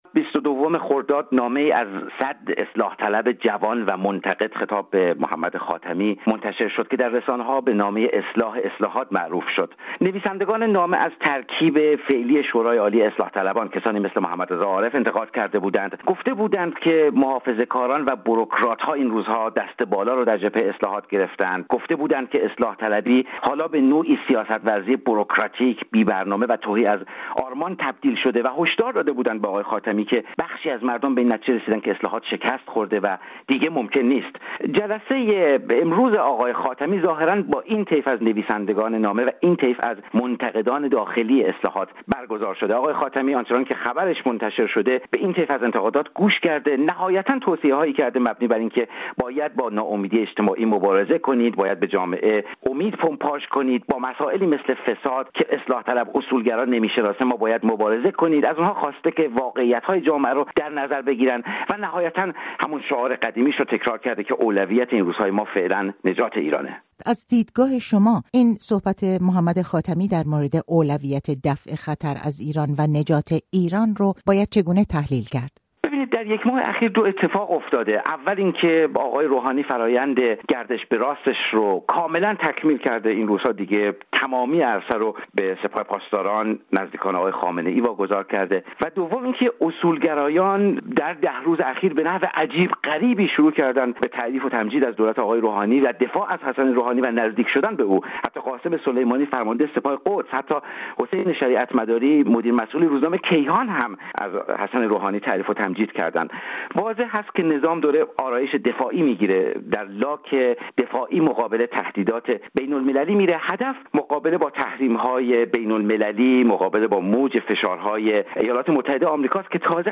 در گفت‌وگو با رادیوفردا به پرسش‌هایی در این مورد پاسخ داده است.